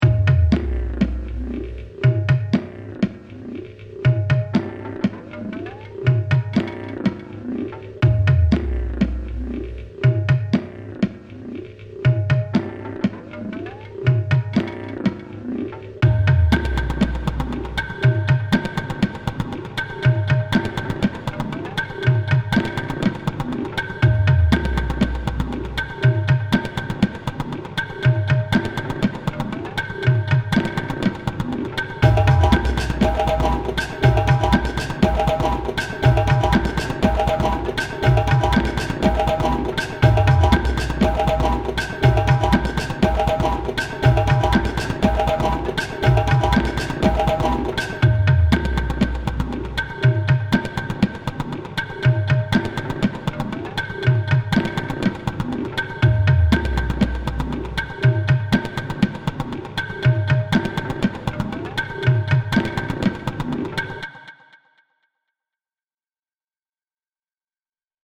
Très bonnes musiques d’ambiances, cela lance le voyage de belle manière, bravo.